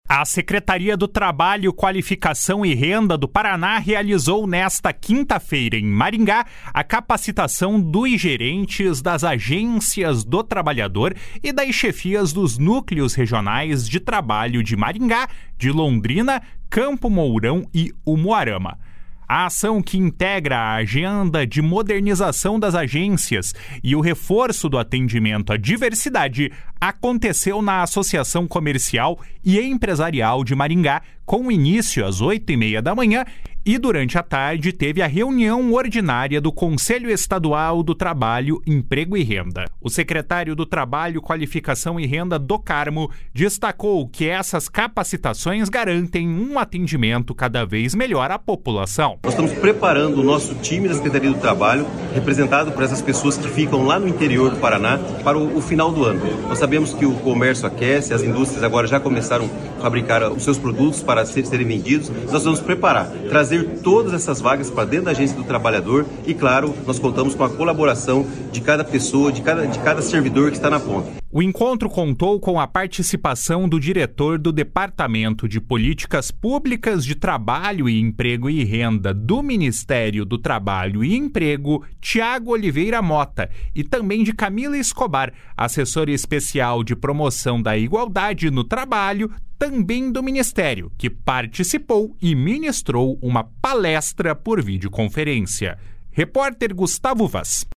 O secretário do Trabalho, Qualificação e Renda, Do Carmo, destacou que essas capacitações garantem um atendimento cada vez melhor à população. // SONORA DO CARMO //